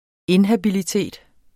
inhabilitet substantiv, fælleskøn Bøjning -en Udtale [ ˈenhabiliˌteˀd ] Oprindelse af in- og latin habilitas (genitiv -atis ) 'hensigtsmæssig egenskab', jævnfør habil Betydninger 1.